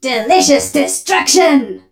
pearl_atk_vo_07.ogg